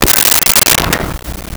Glass Bottle Break 03
Glass Bottle Break 03.wav